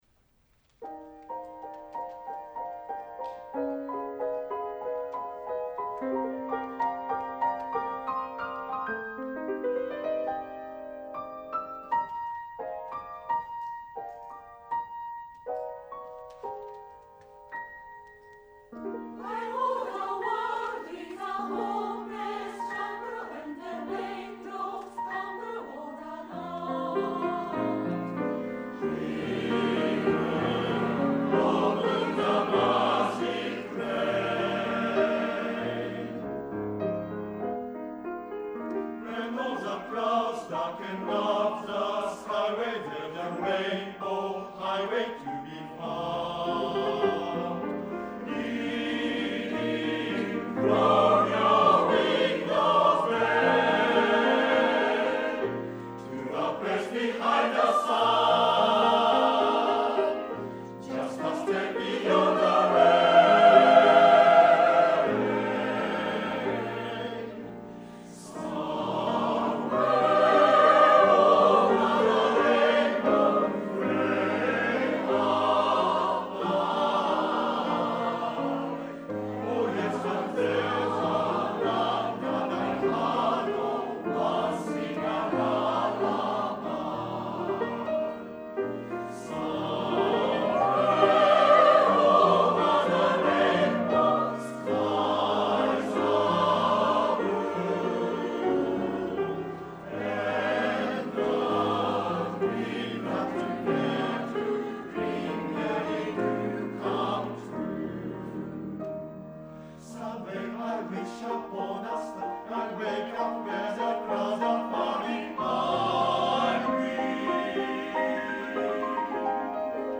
第４４回　Ｆ合唱祭　各団体の演奏